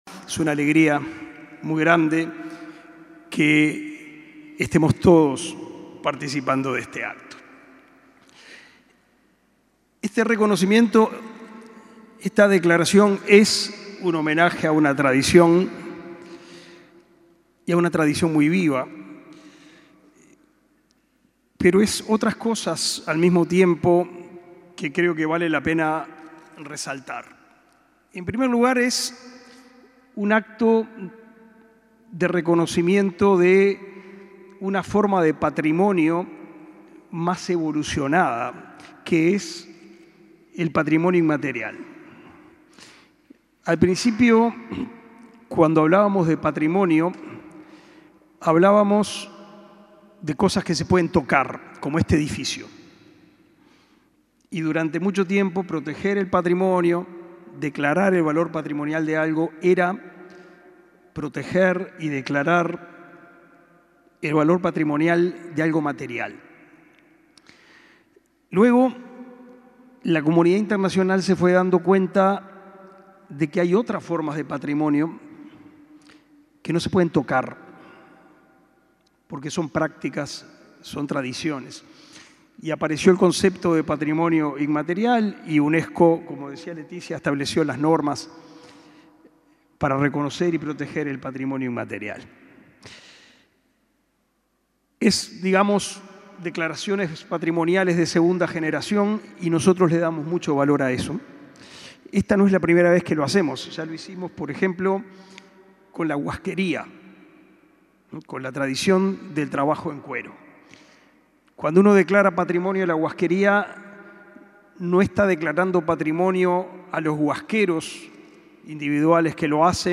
Palabras de autoridades en acto del MEC
Palabras de autoridades en acto del MEC 15/06/2023 Compartir Facebook X Copiar enlace WhatsApp LinkedIn El titular del Ministerio de Educación y Cultura (MEC), Pablo da Silveira, y la vicepresidenta de la República, Beatriz Argimón, participaron en el Palacio Legislativo en el acto en que se declaró al pericón nacional Patrimonio Cultural Inmaterial del Uruguay.